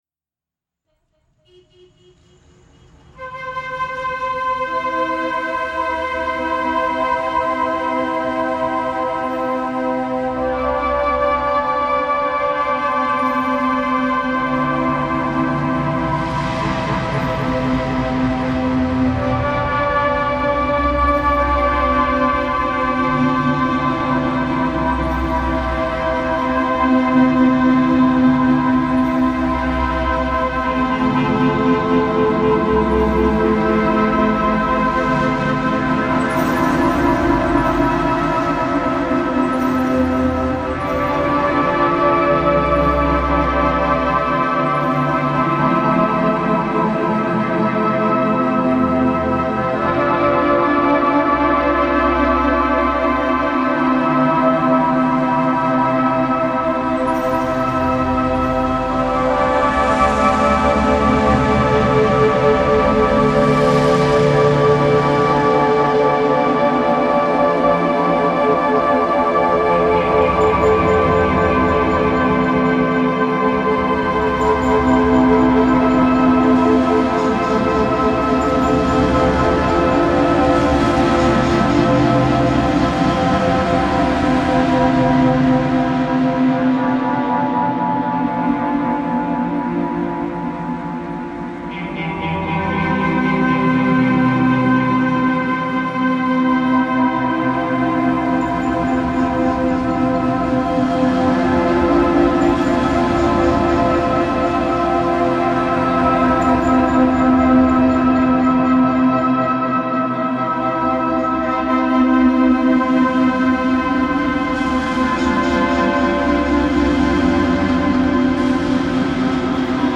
Yaounde street soundscape reimagined